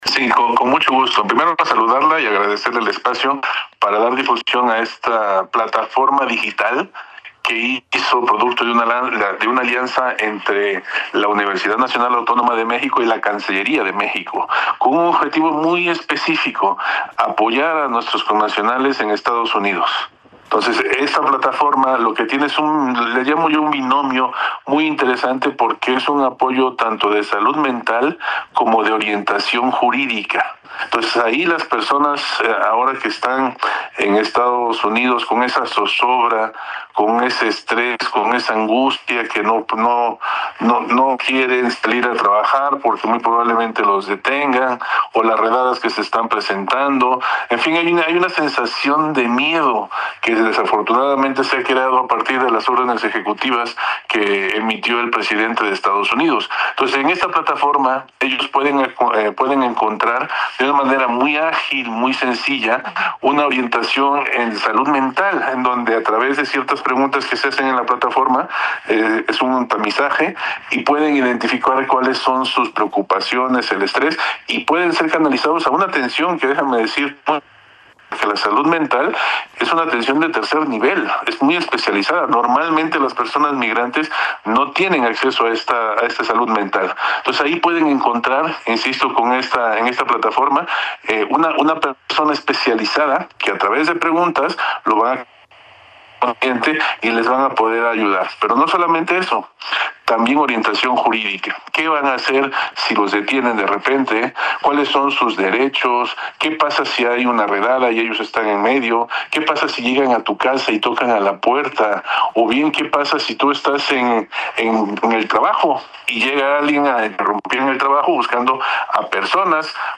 17-ENTREVISTA-UNAM-.mp3